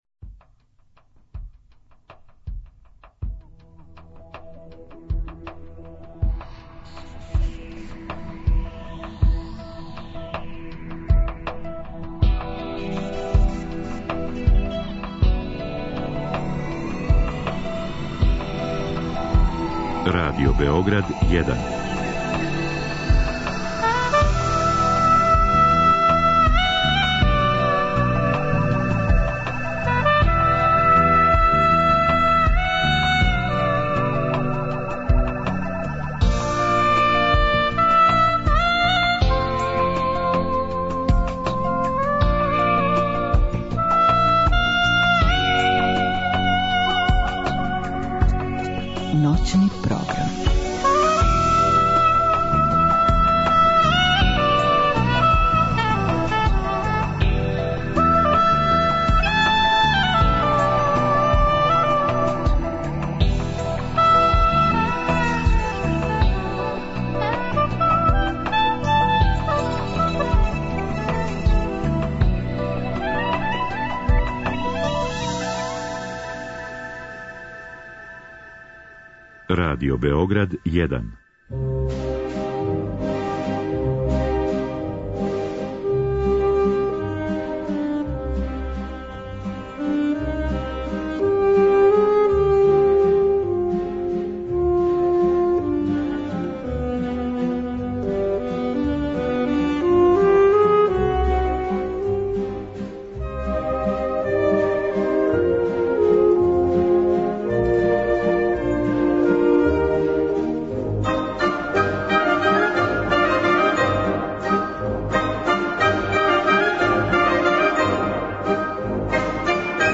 У другом сату имаћете прилику да чујете делове излагања са трибине "Нови звучни простори", која је 3.марта била посвећена остварењу "Свемир" Југослава Бошњака.
Термин од 2-4 резервисан је за камерна дела која су компоновали Волфганг Амадеус Моцарт, Фредерик Шопен и Антонио Лауро.